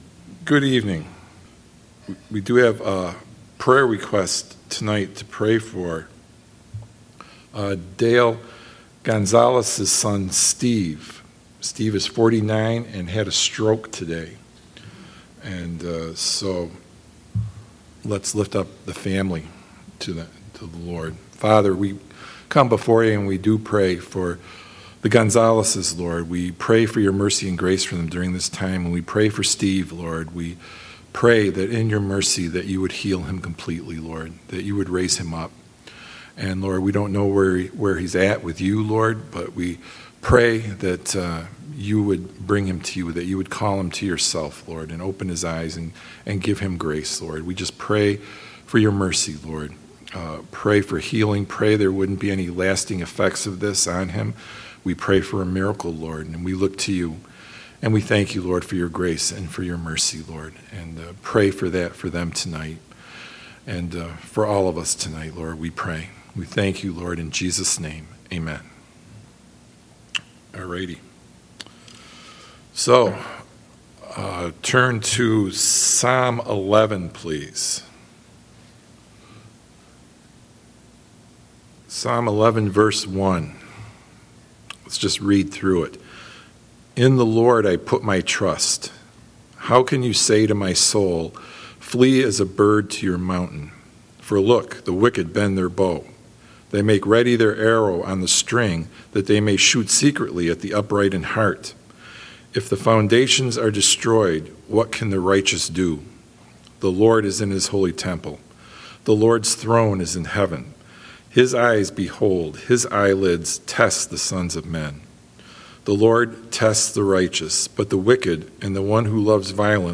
Sunday Night Bible Study